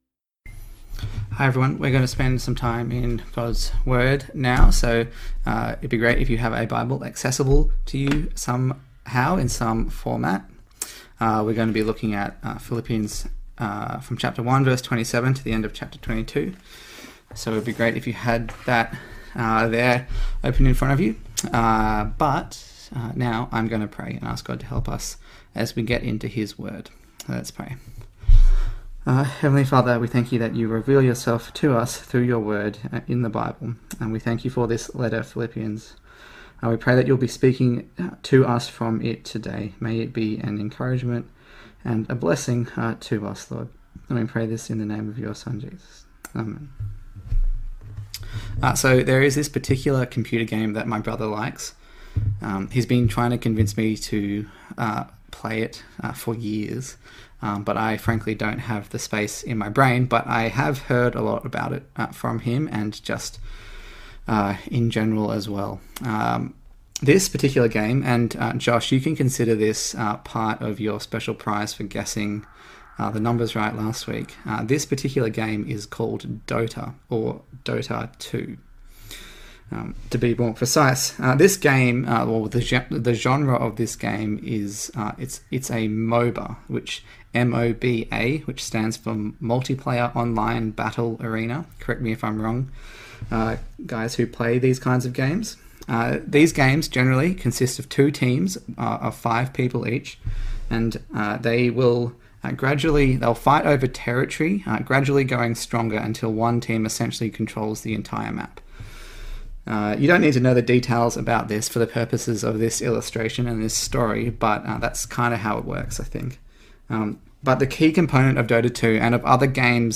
Philippians Passage: Philippians 1:27-2:30 Service Type: Sunday Morning